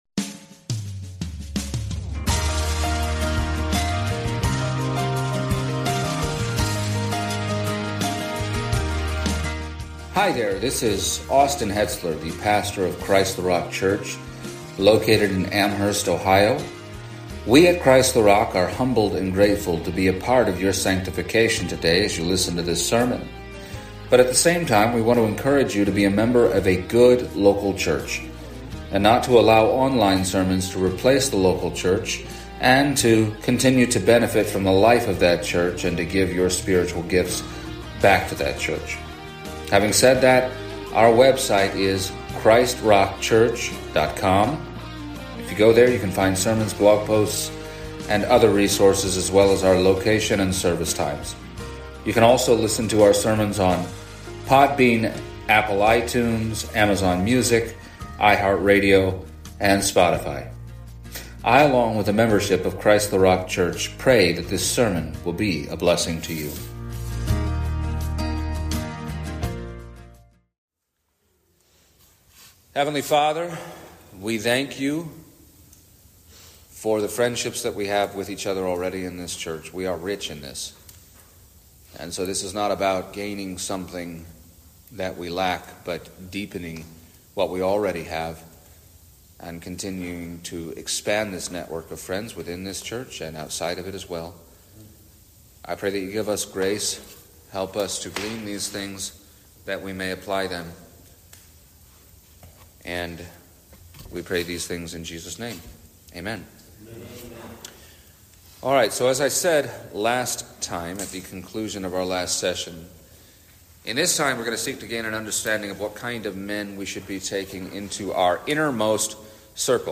The second of three messages given at the inaugural CtRC Men’s Summit